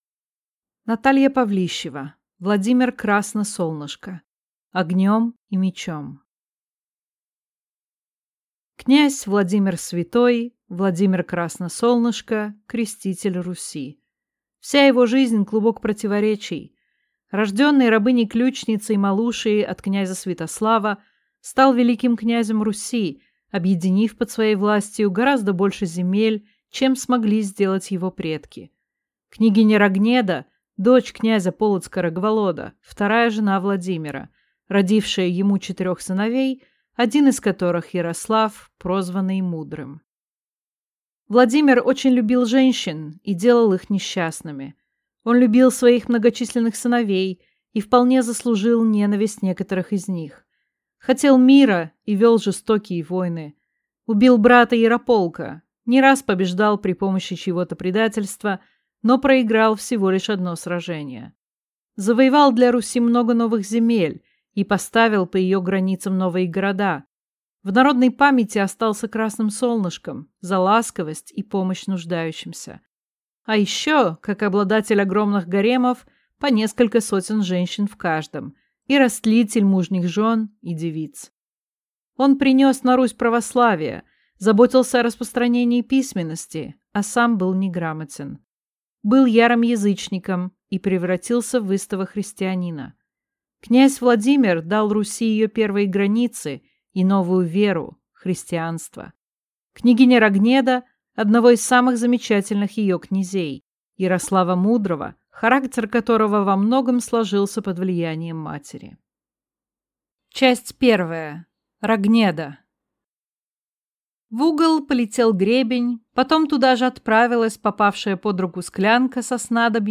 Аудиокнига Владимир Красно Солнышко. Огнем и мечом | Библиотека аудиокниг